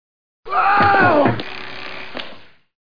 screamfall2.mp3